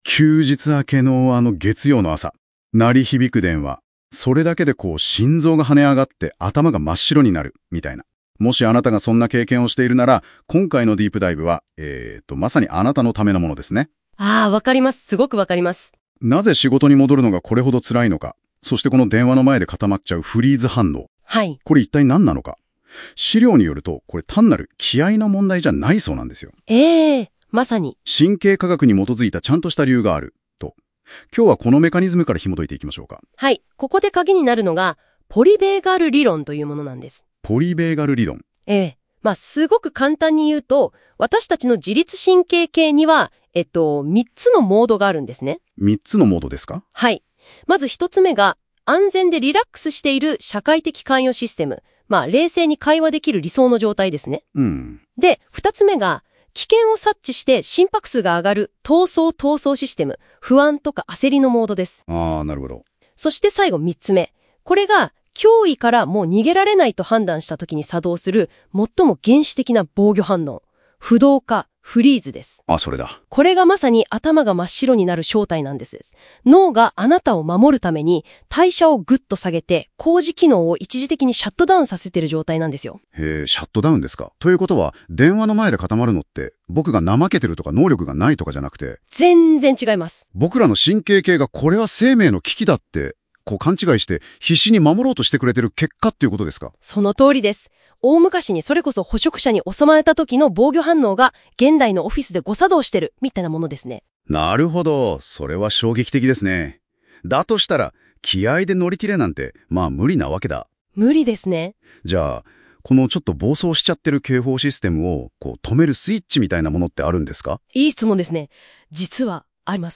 音声解説を追加しました。休み明け・連休明けは本当に辛い。